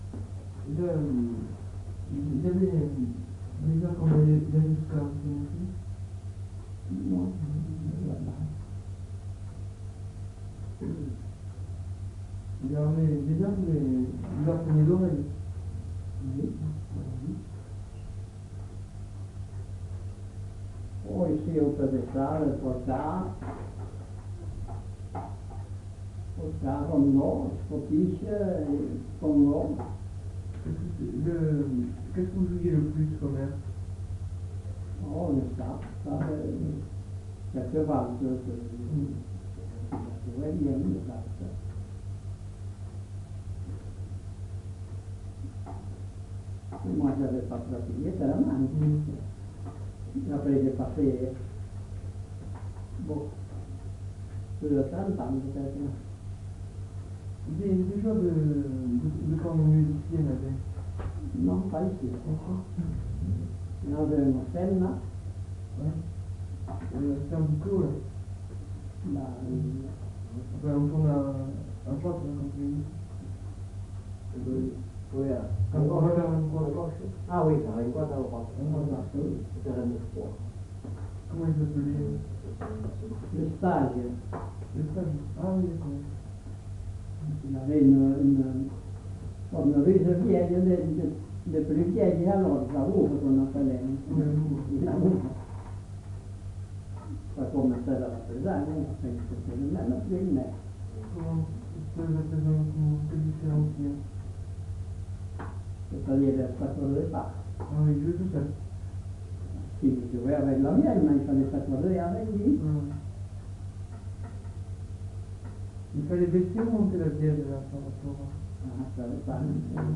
Lieu : Lencouacq
Genre : témoignage thématique